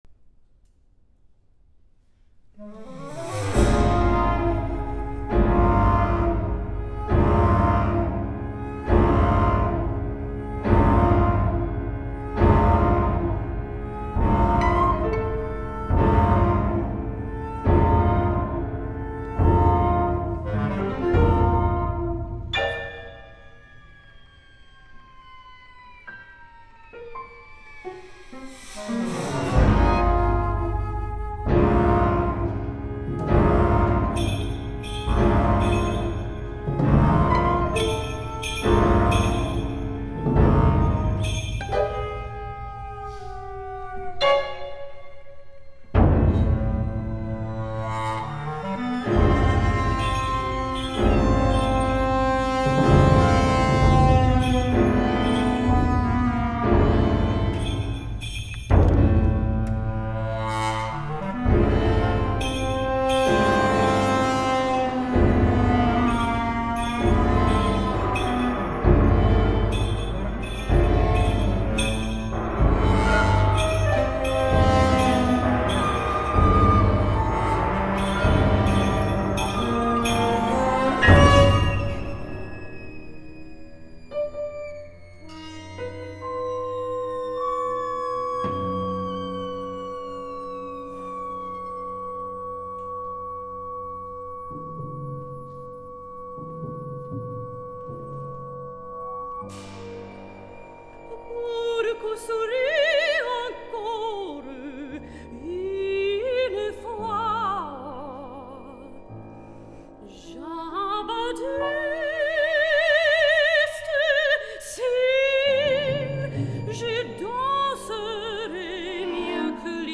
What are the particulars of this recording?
Recordings from various performances in my career